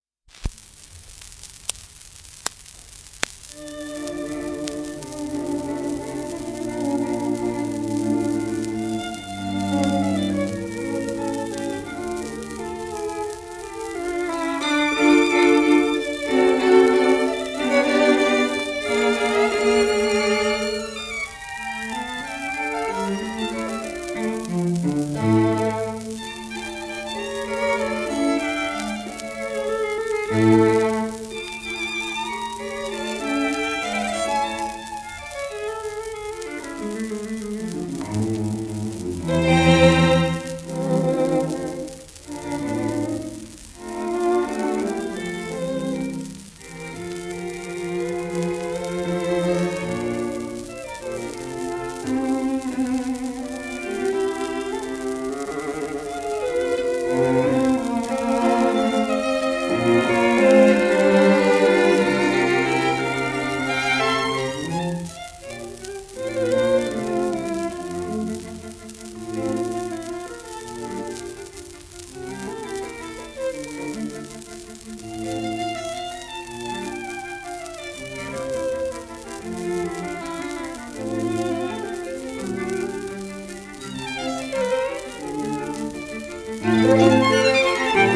グリラー弦楽四重奏団
盤質A- *１面目導入部にキズ(ギリギリ音溝に入らない程度：試聴音源でご確認下さい)
戦後録音、デッカカーブ(ffrr)表記
シェルマン アートワークスのSPレコード